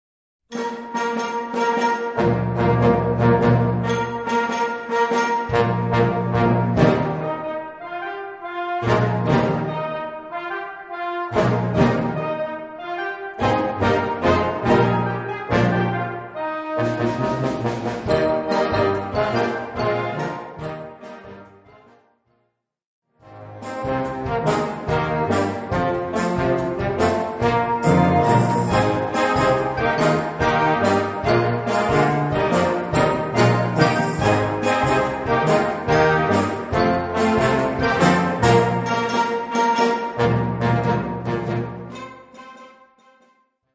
Kategorie Blasorchester/HaFaBra
Besetzung Ha (Blasorchester); Flexi (variable Besetzung)
Besetzungsart/Infos 4Part; Perc (Schlaginstrument)